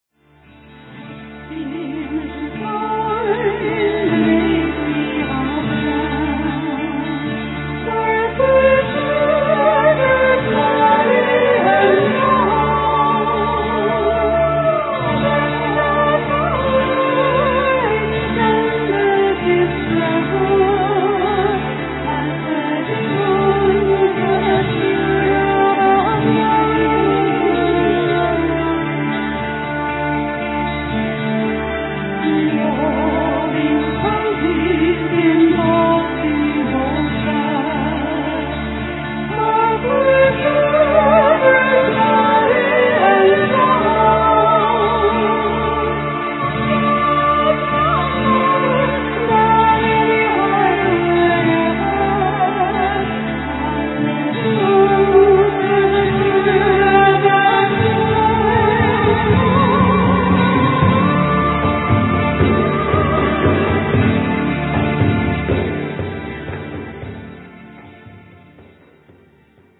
vocals, flute
classic, acoustic and electric guitars, chitarra battente
keyboards